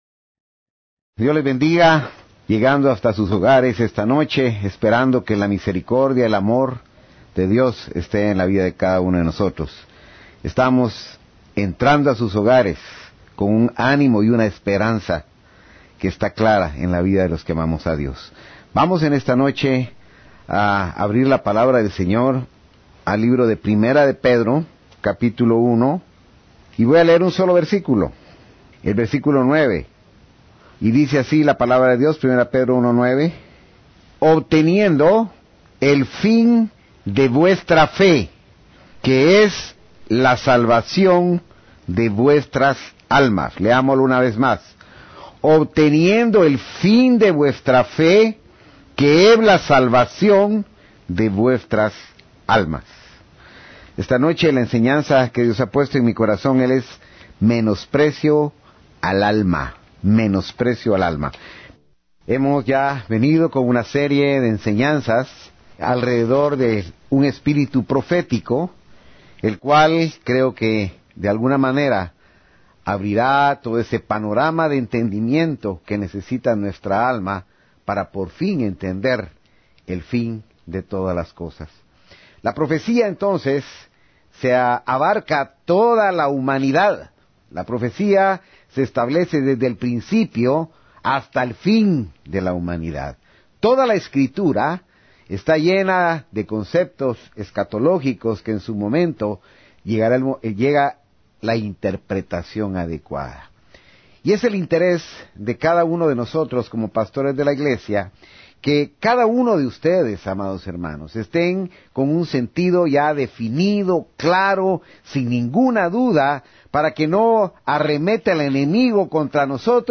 Audio de la Prédica